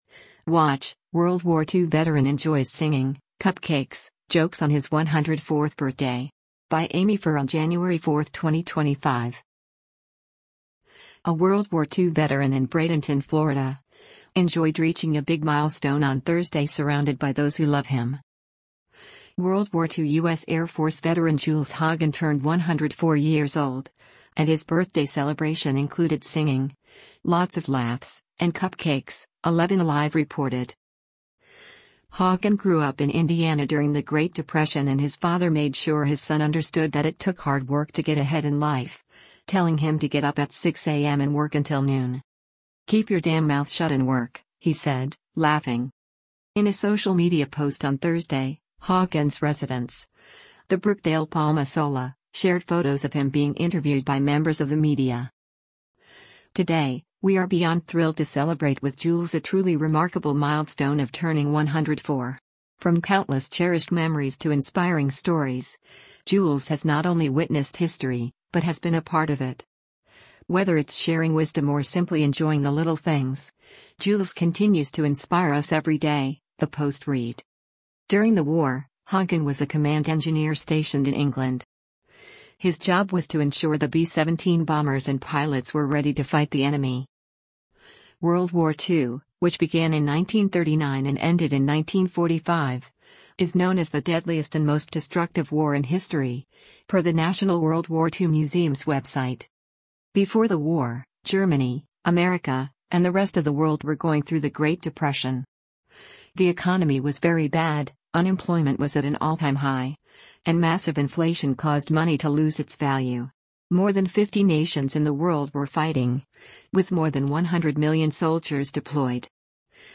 WATCH: WWII Veteran Enjoys Singing, Cupcakes, Jokes on His 104th Birthday